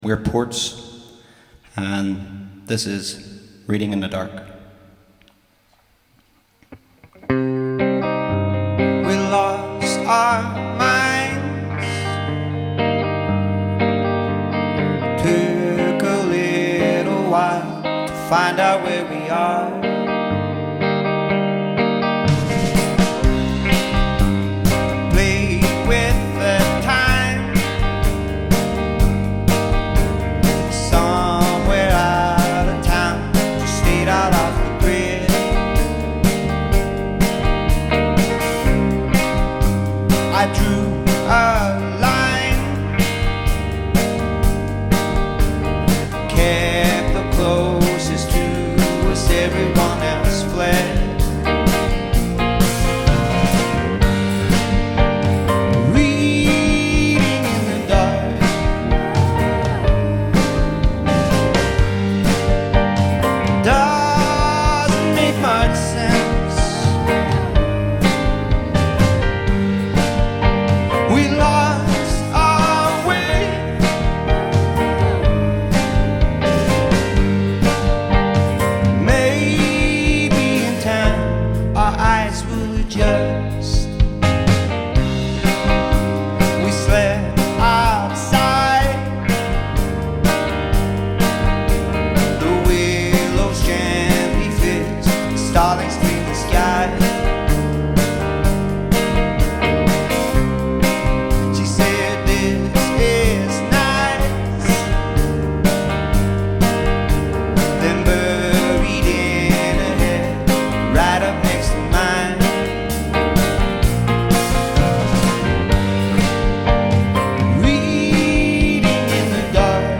live concert minus audience due to lockdown
Bass and Vocals
Drums
Guitar
Piano